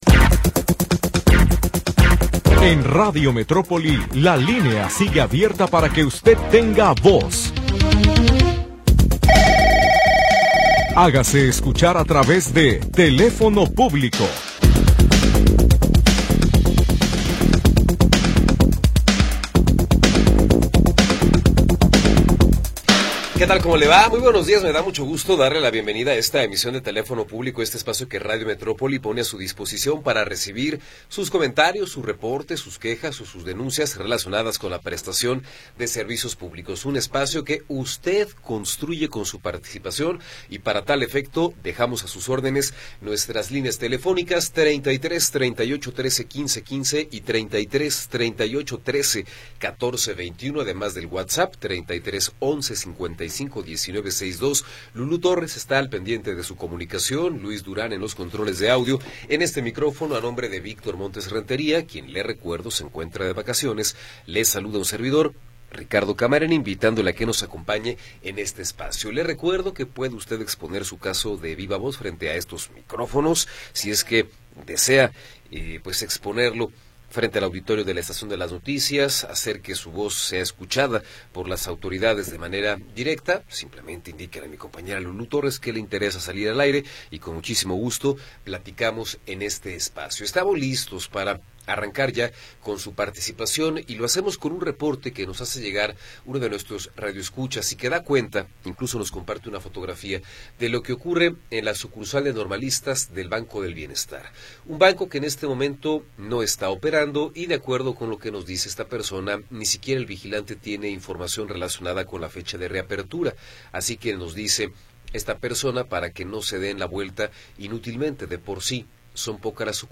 Programa transmitido el 31 de Julio de 2025.